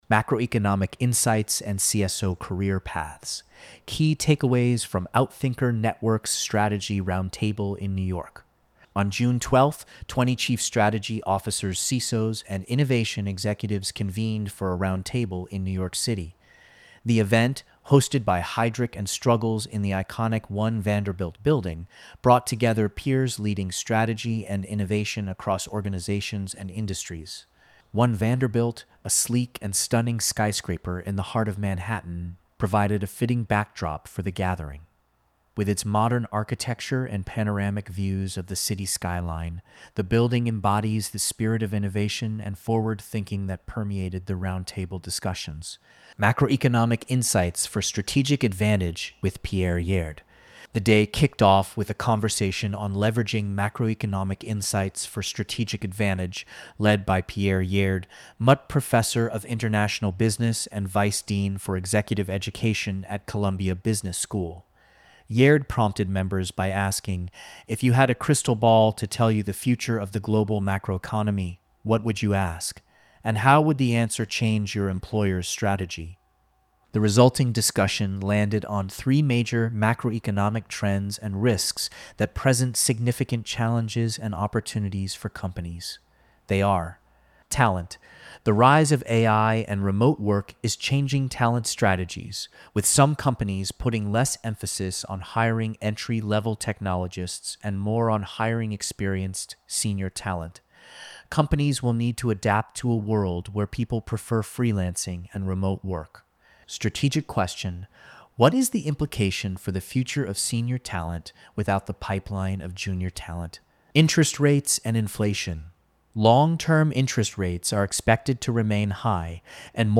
This audio was recorded by an AI program.